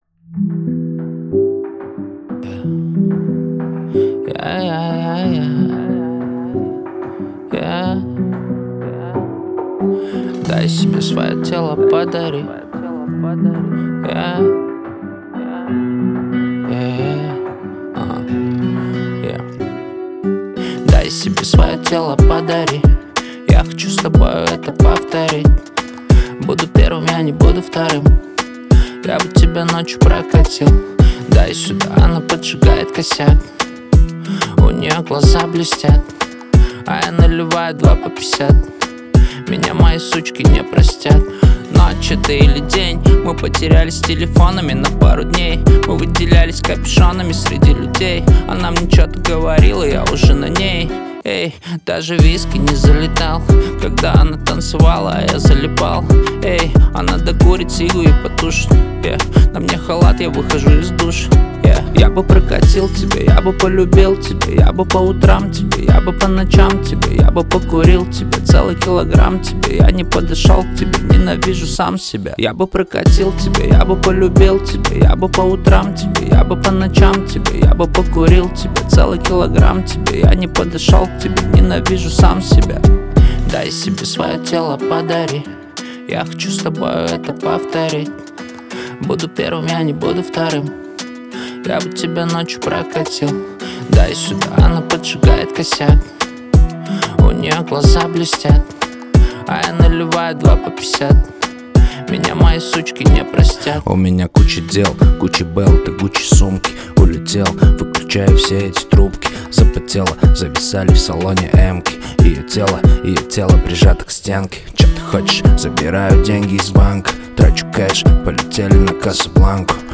Казахские песни